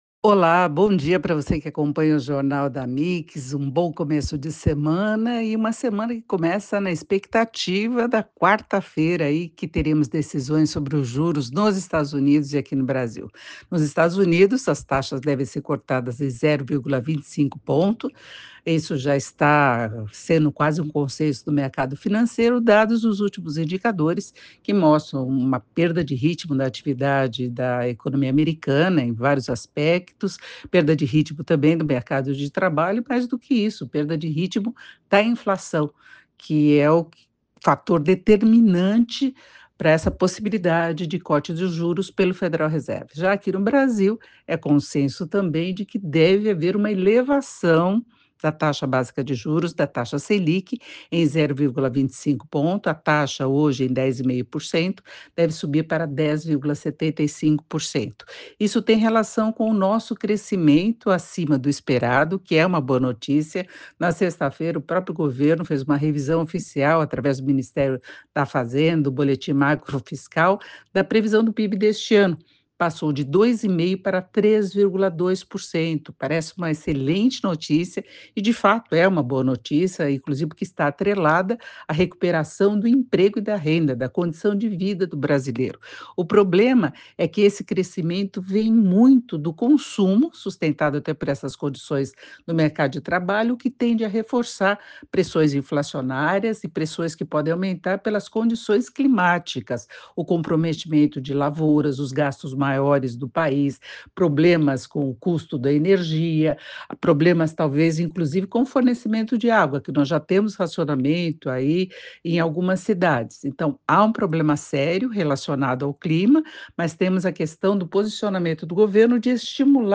Categoria: Coluna
Periodicidade: 5 vezes por semana (segunda a sexta), gravada